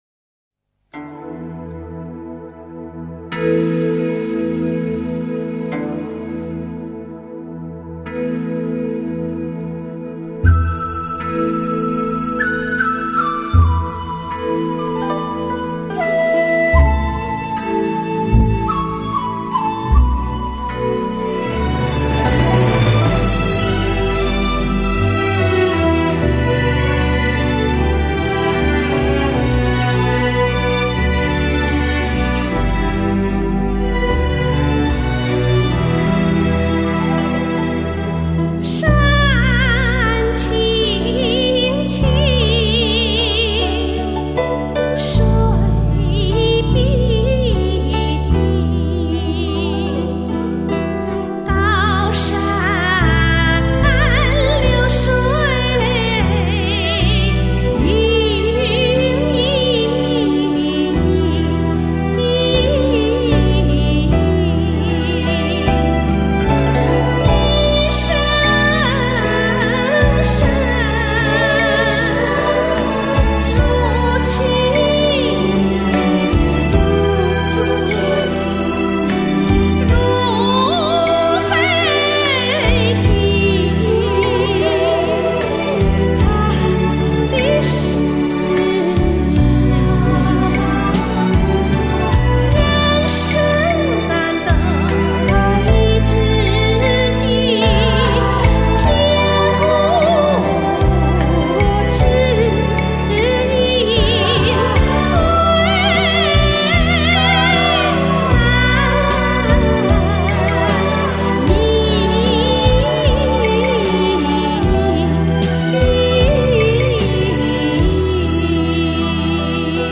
音乐类型:中国歌曲